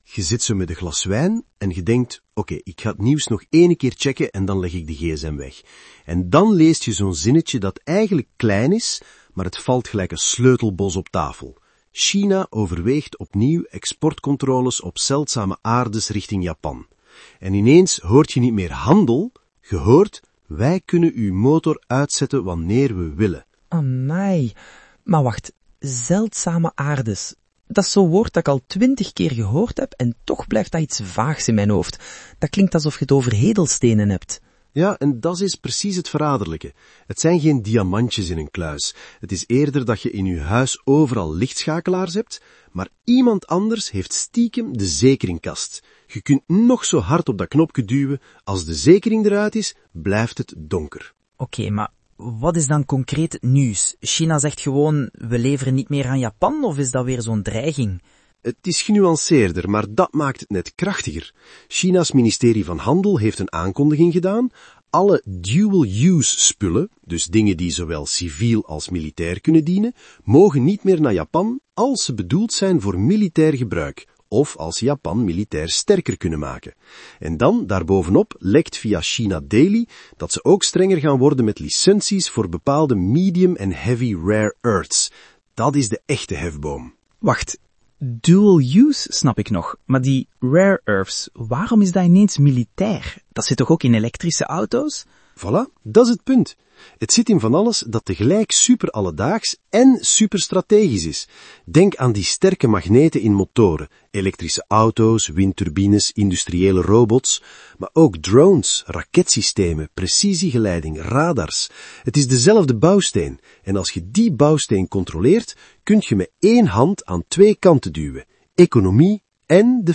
… continue reading 12 episodes # Tech # AIgenerated Podcast